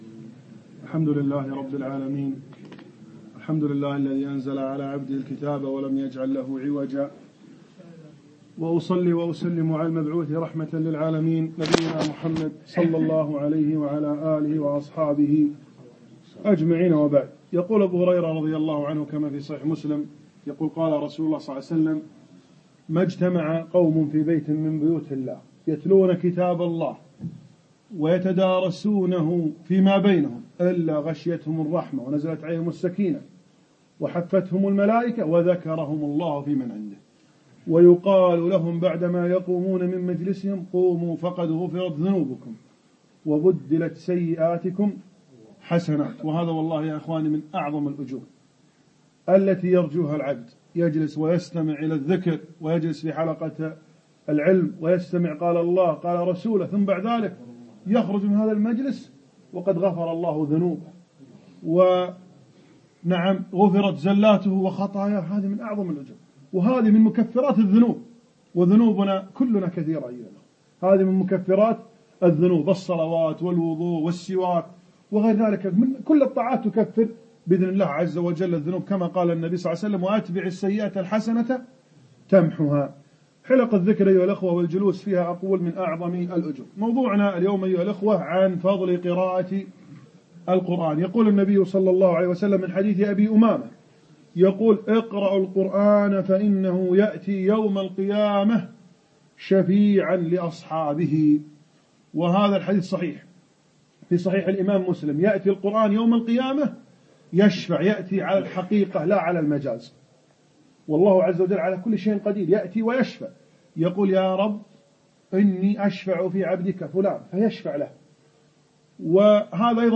فضل القرآن - كلمة